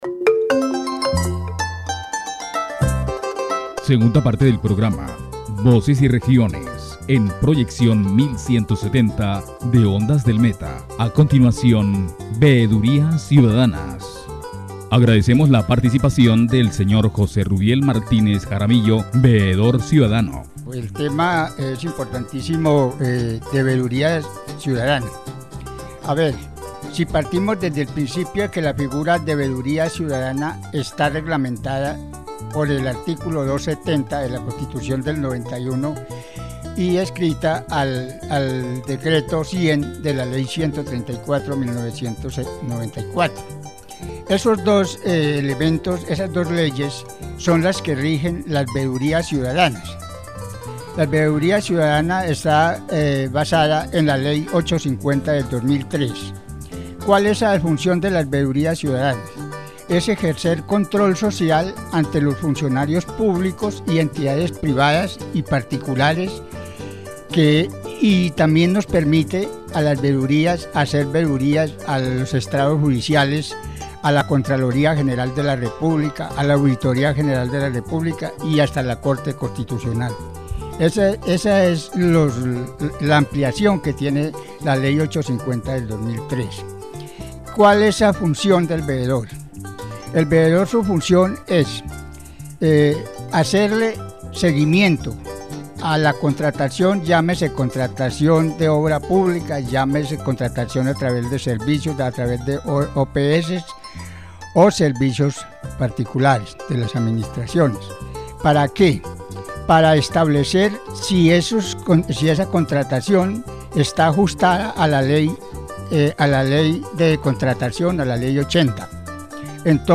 The radio program "Voices and Regions" delves into the concept of citizen oversight in Colombia.
The debate highlights the challenges faced by oversight bodies, such as corruption, lack of resources and political interference. The key topics discussed are: purpose of Citizen Oversight, challenges faced by oversight, importance of community participation and its legal framework.